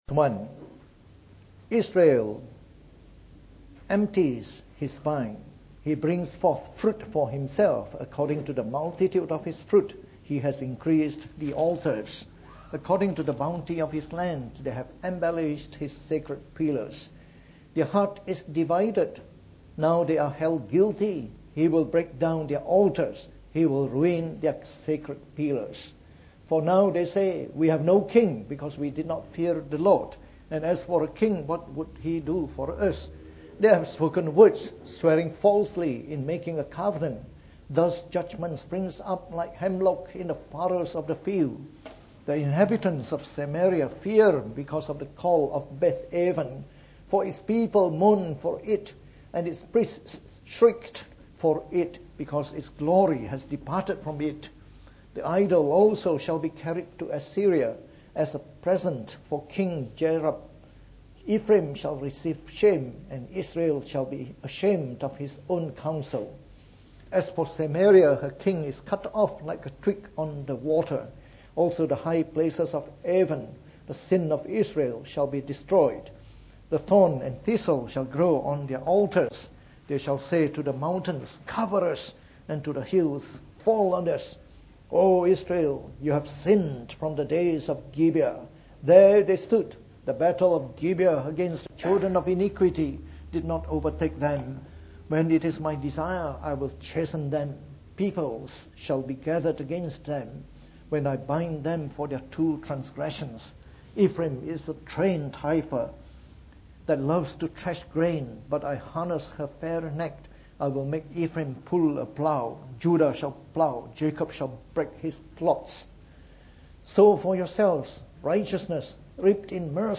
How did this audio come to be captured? From our series on the Book of Hosea delivered in the Morning Service.